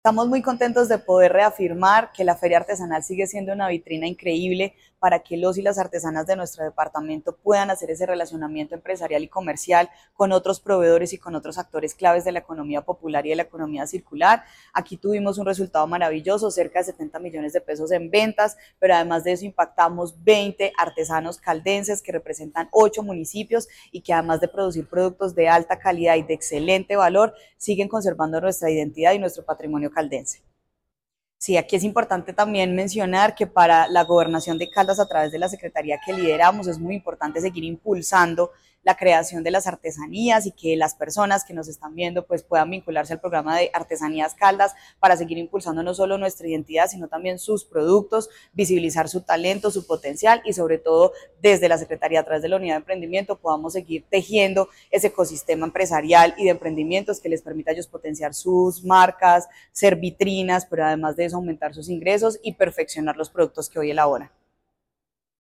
Juanita Espeleta Noreña, secretaria de Desarrollo, Empleo e Innovación.
Juanita-Espeleta-Norena-Secretaria-de-Desarrollo-Empleo-e-Innovacion-de-Caldas1.mp3